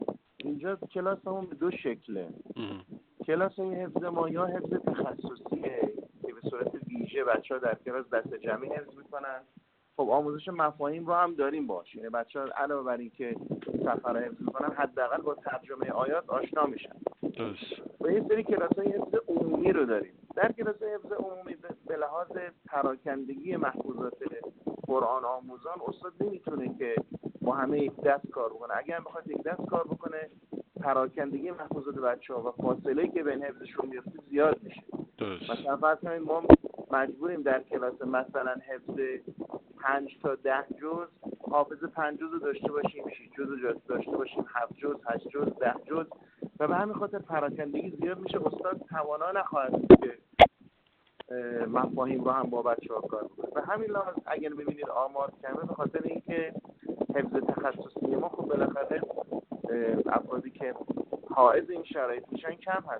در گفت‌و‌گو با خبرنگار ایکنا، با اشاره به استقبال چشمگیر متقاضیان شرکت در دوره‌های آموزشی این مرکز گفت